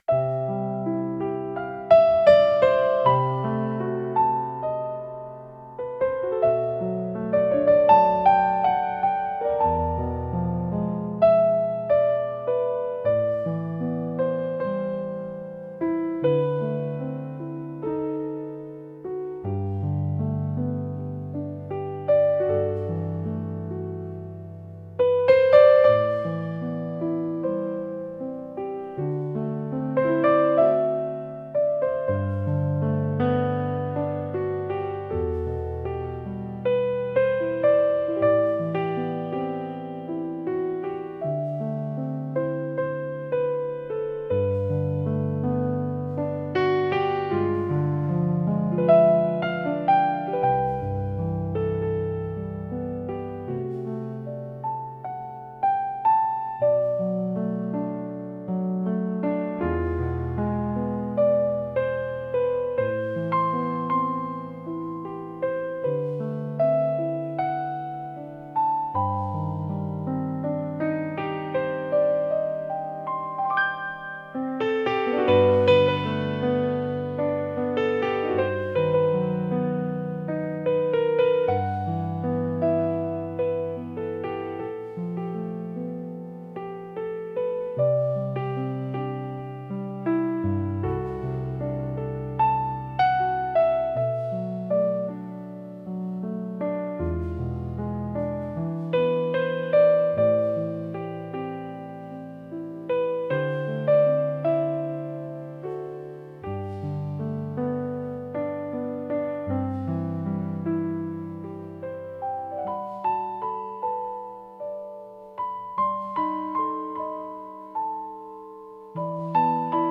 シンプルで美しい旋律がゆったりと流れ、静かな回想や感慨深いシーンに深みを加えます。
聴く人にやすらぎと温かみを提供し、心を穏やかに整える効果があります。繊細で情感豊かな空気を醸し出すジャンルです。